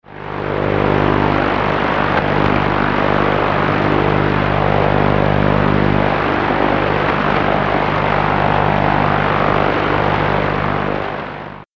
Buzz-Pulse